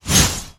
iceshard2.wav